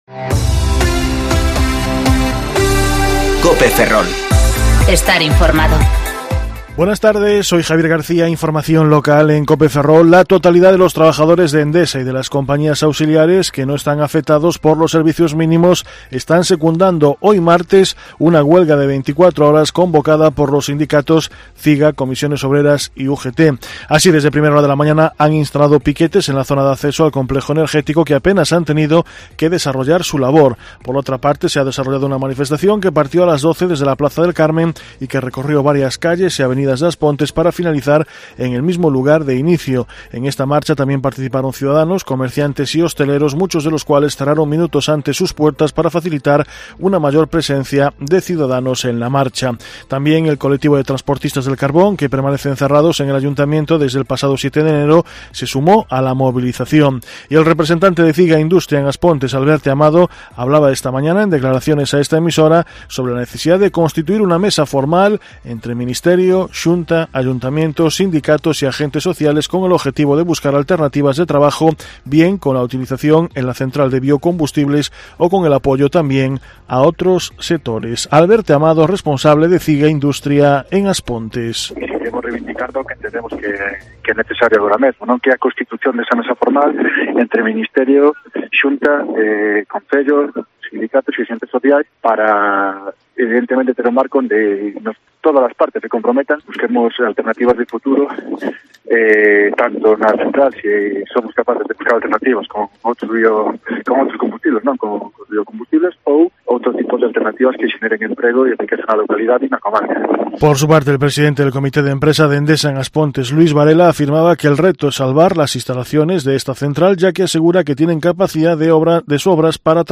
Informativo Mediodía COPE Ferrol 14/1/2020 (De 14,20 a 14,30 horas)